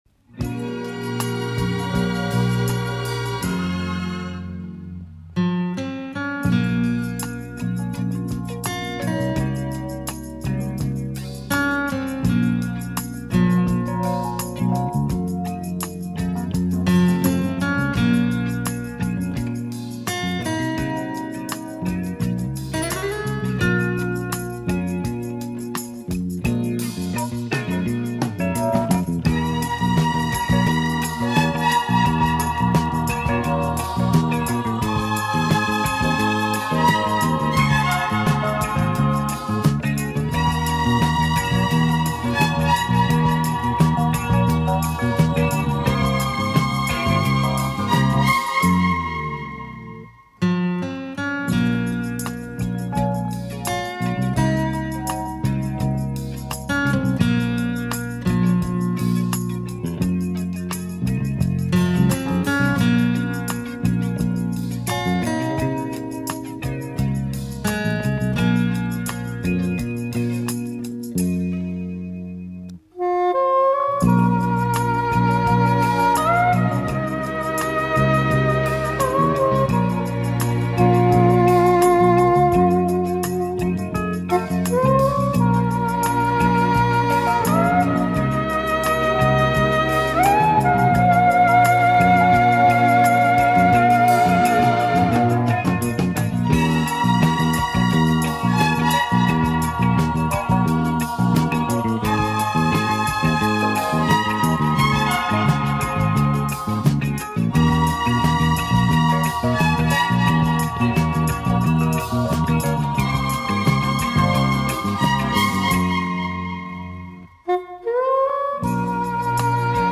Романтичная музыка